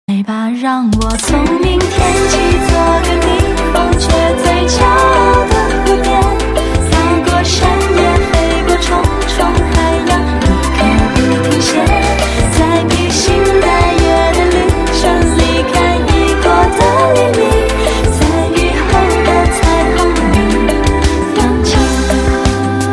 M4R铃声, MP3铃声, 华语歌曲 86 首发日期：2018-05-15 09:32 星期二